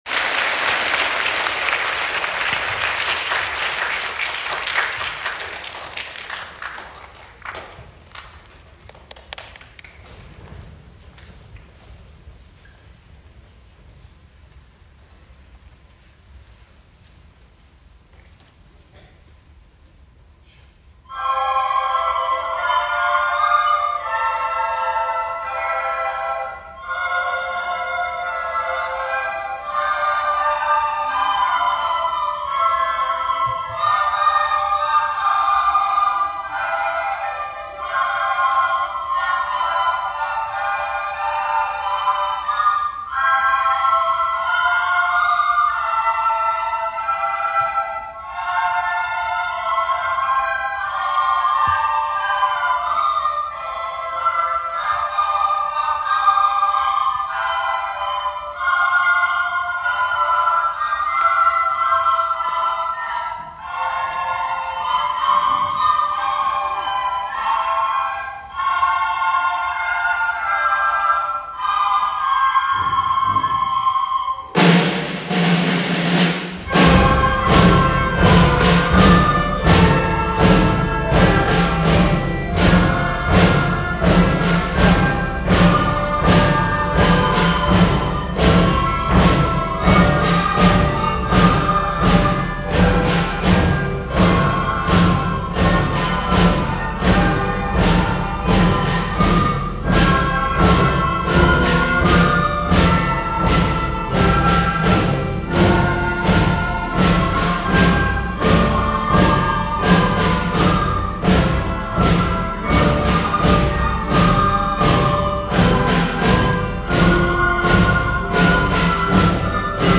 １１月８日（水）、４年生が野々市市音楽会に出演しました。
一曲目「合奏　威風堂々」